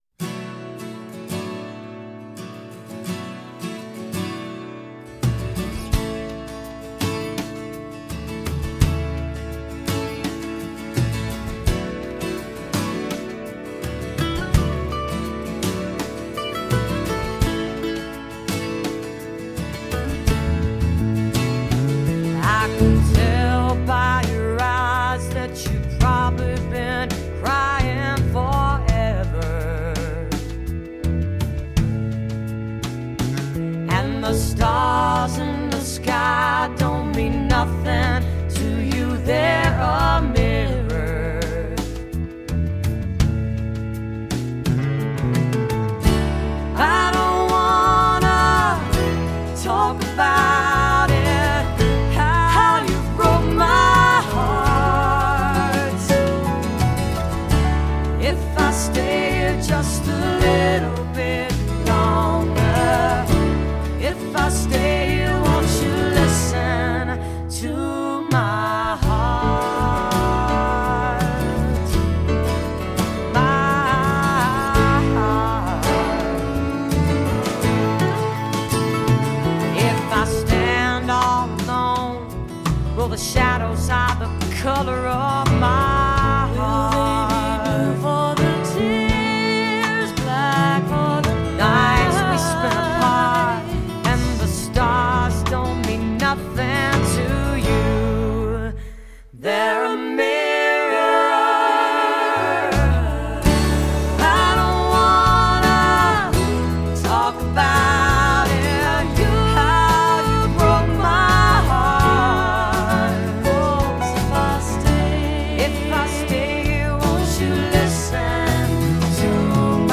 chicas folk, folk rock norteamericanas